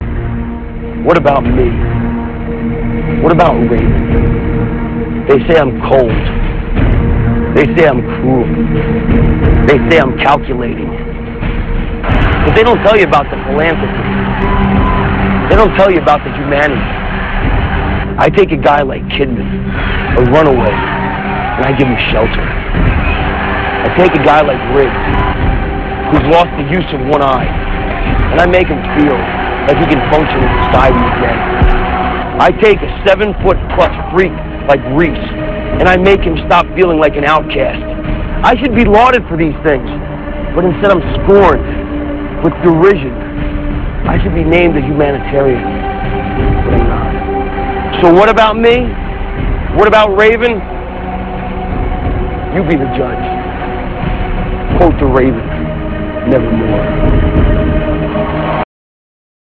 - This speech comes from Thunder - [7.2.98]. This is the "Happiness is Helping Others" promo.